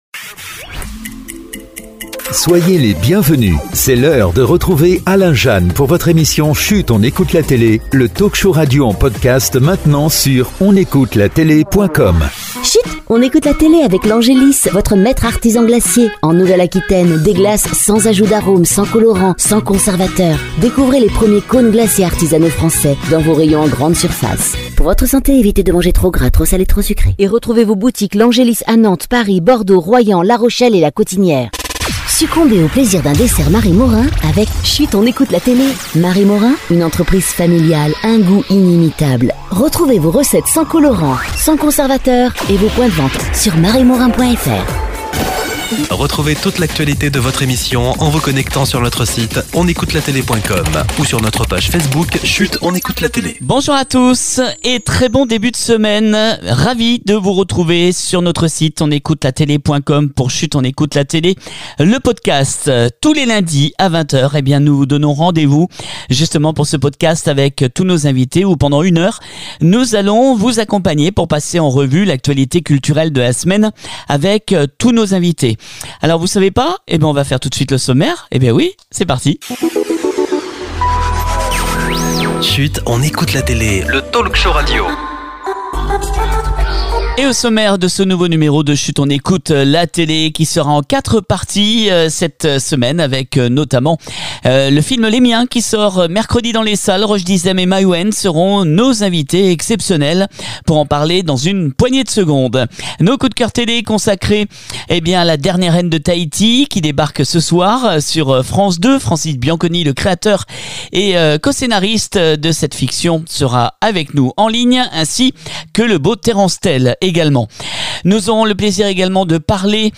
puis coup de coeur cinéma pour le film “Reste un peu” avec Gad Elmaleh qui est notre invité